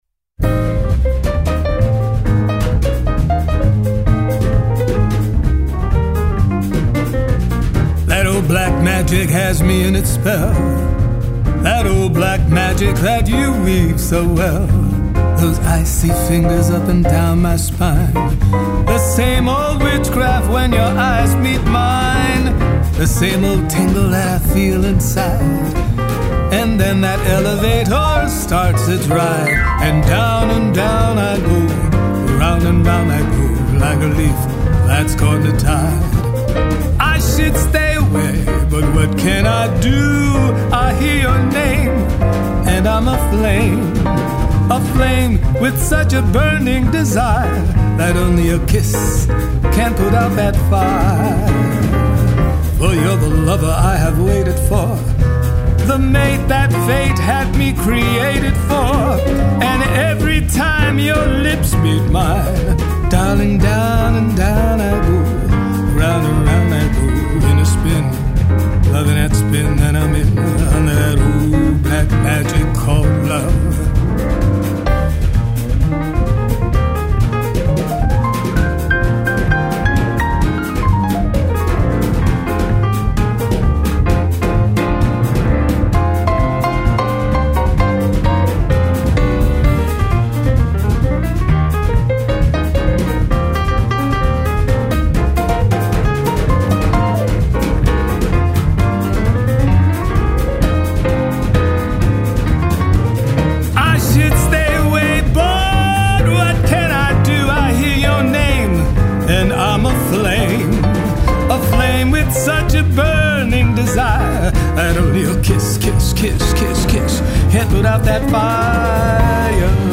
the glorious sounds of hot jazz and great music